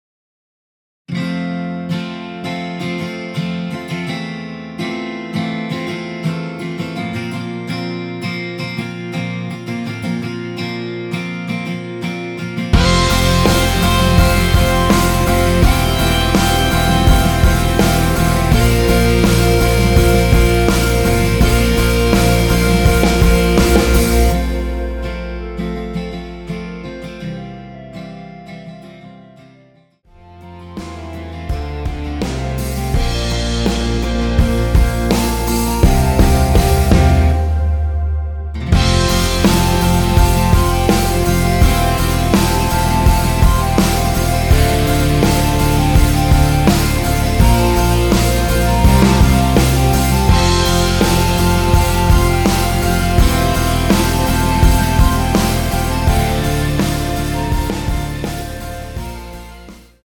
원키에서(+5)올린 (1절앞+후렴)으로 진행되게 편곡된 멜로디 포함된 MR입니다.(미리듣기 확인)
앞부분30초, 뒷부분30초씩 편집해서 올려 드리고 있습니다.